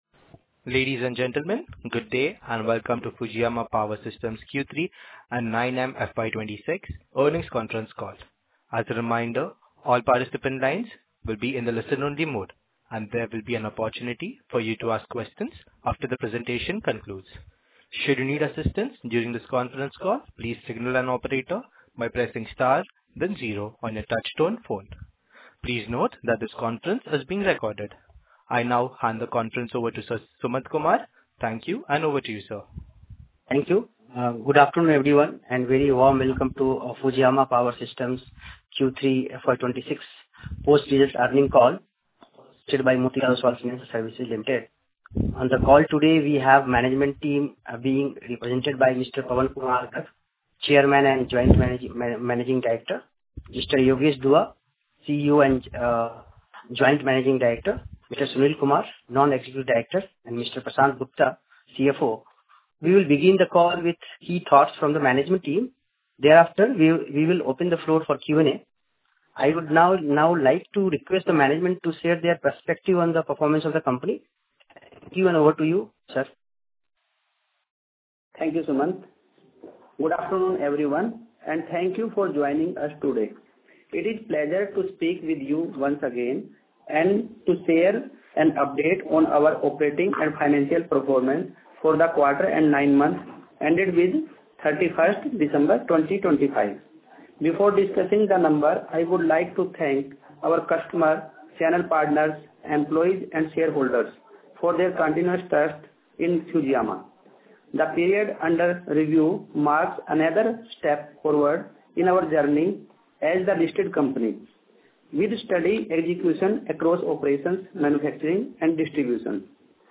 Q3 Earnings ConCall Recording.mp3